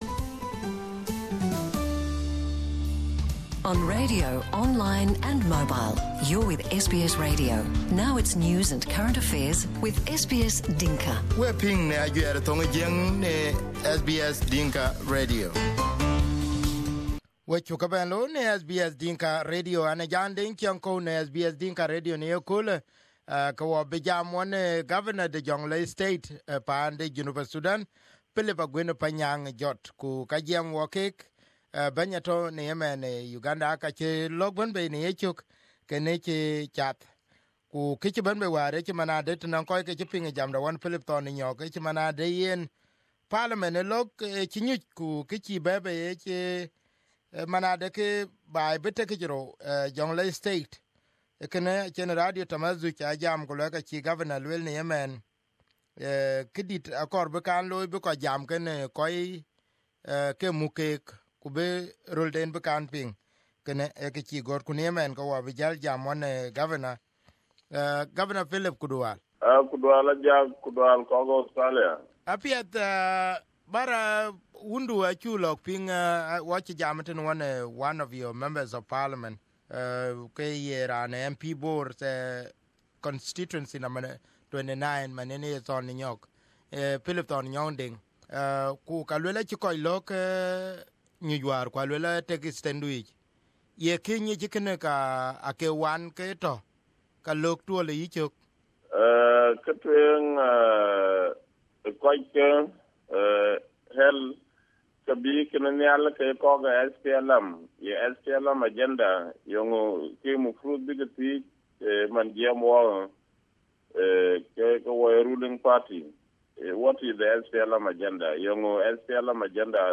Here is the interview today on SBS Dinka Radio.